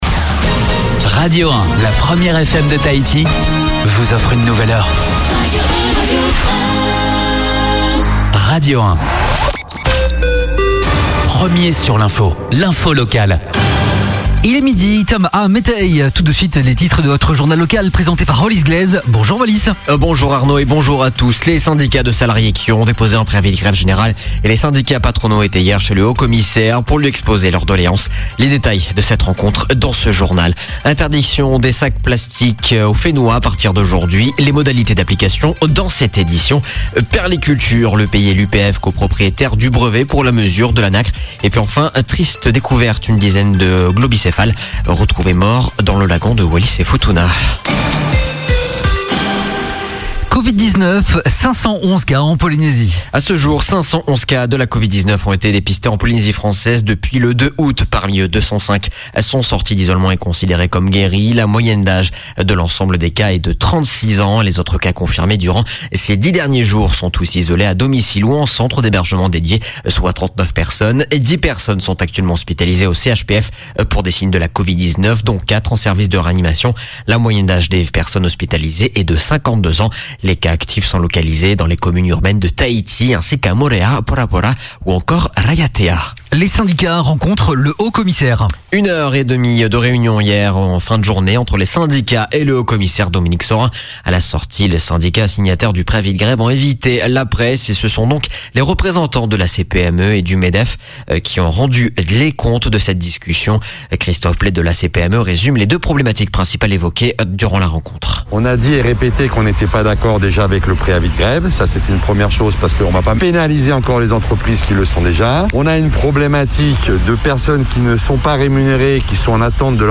Journal de 12:00, le 01/09/2020